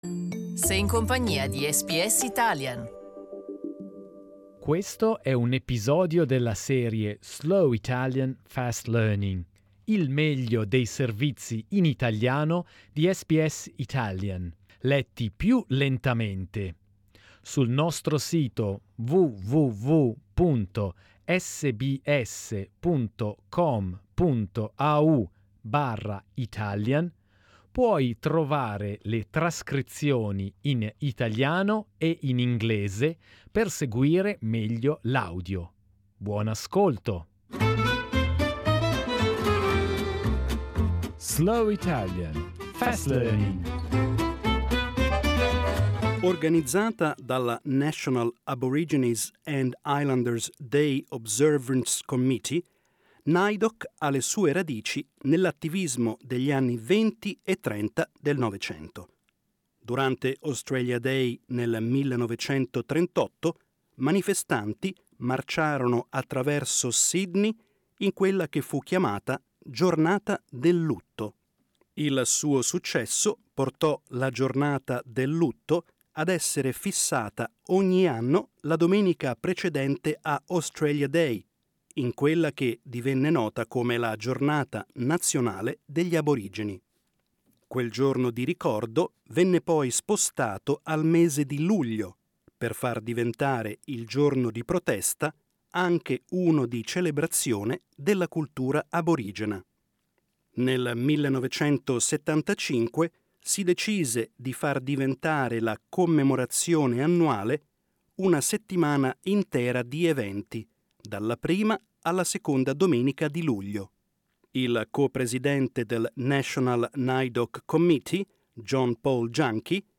Slow Italian, Fast Learning , il meglio dei nostri servizi della settimana, letti più lentamente e più scanditi , con i testi in italiano e in inglese .